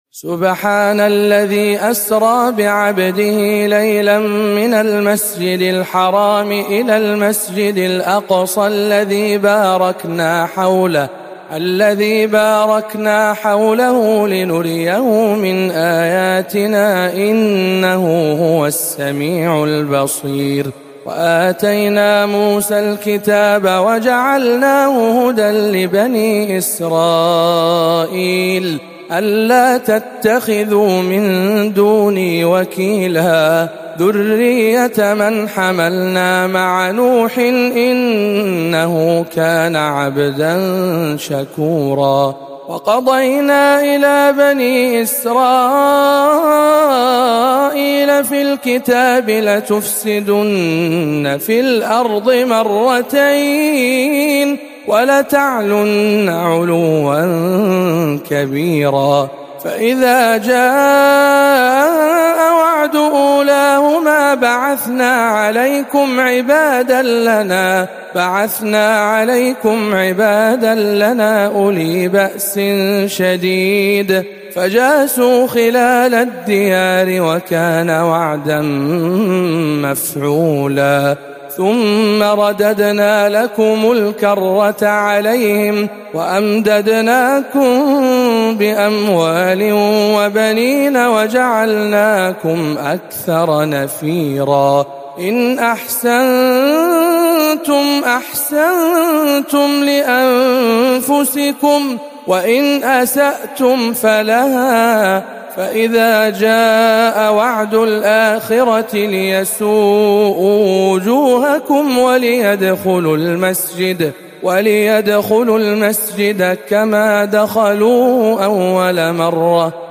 سورة الإسراء بجامع أم الخير بجدة - رمضان 1439 هـ